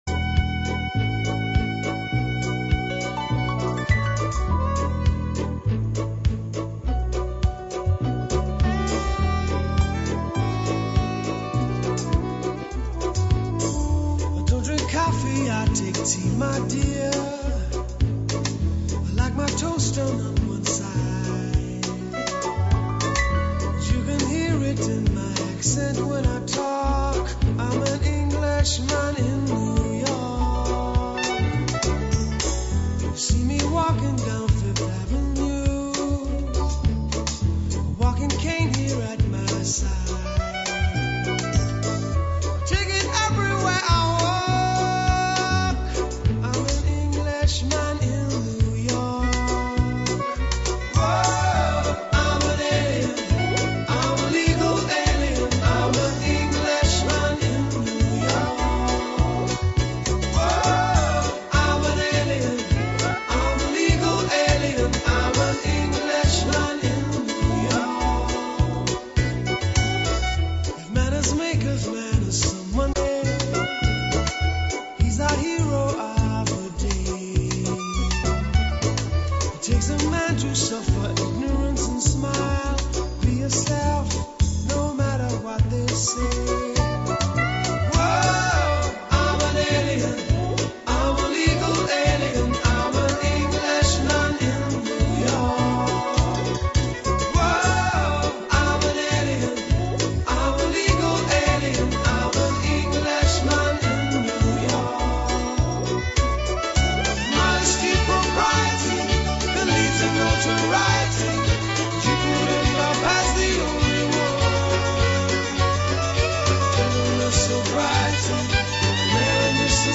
Ronda d'entrevistes als diferents caps de llista.